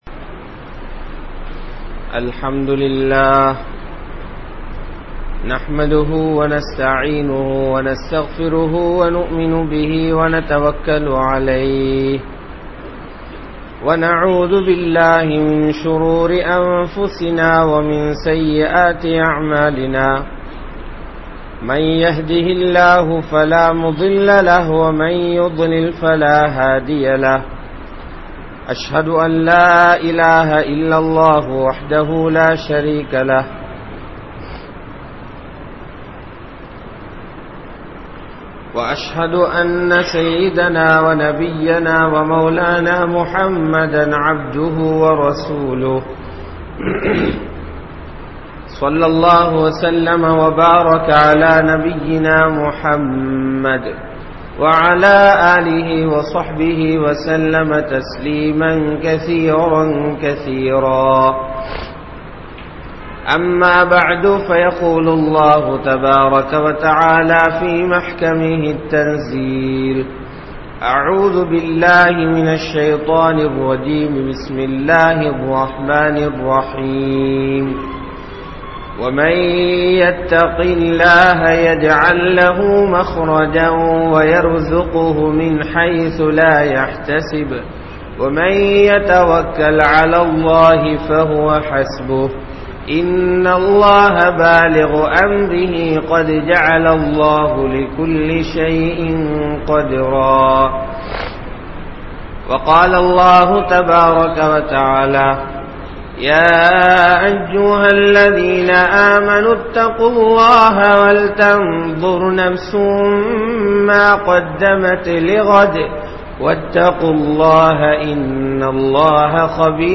Naattin Nilamaikku Kaaranam Yaar? (நாட்டின் நிலமைக்கு காரணம் யார்?) | Audio Bayans | All Ceylon Muslim Youth Community | Addalaichenai
Mallawapitiya Jumua Masjidh